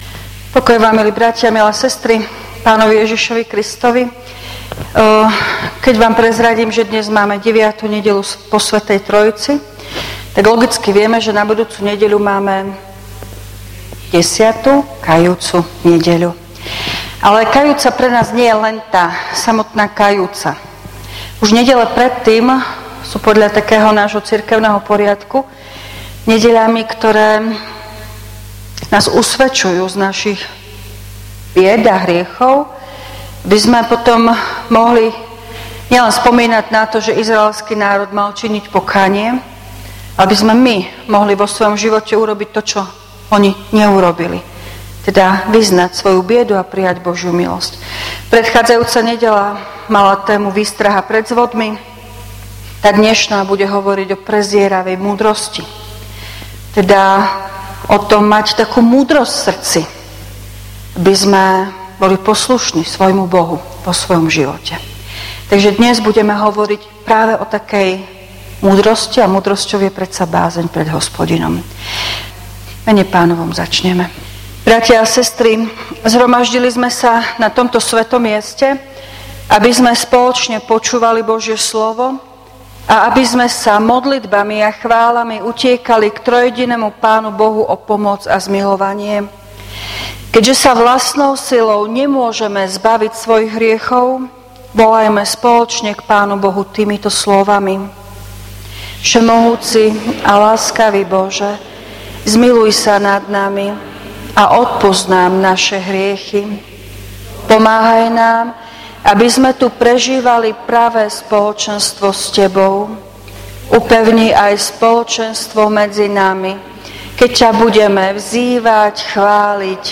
V nasledovnom článku si môžete vypočuť zvukový záznam zo služieb Božích – 9.nedeľa po Svätej Trojici.